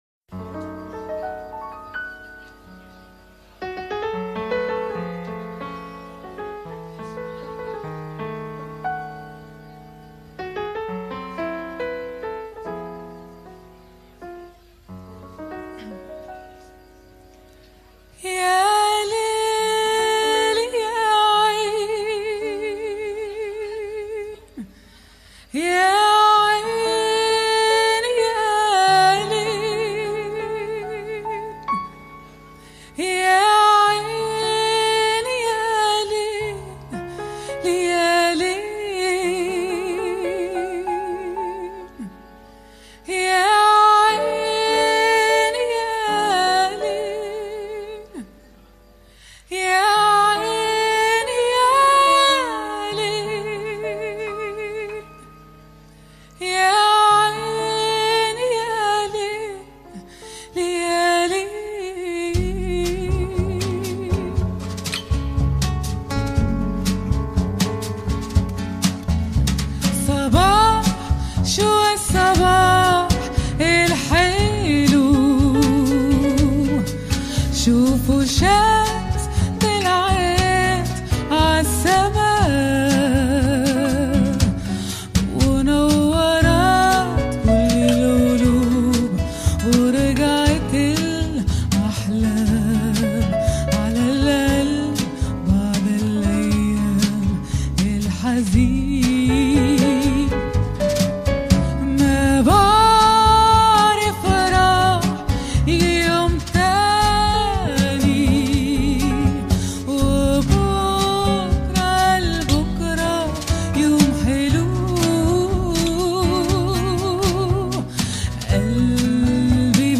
چقدر ارامش بخش+پیانوی عالی